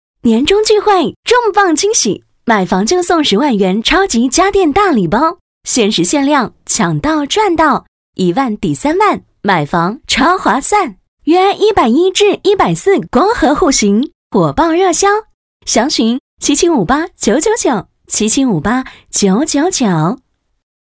• 房地产广告配音
女45-房地产广告【 锦岚地产】
女45-房地产广告【 锦岚地产】.mp3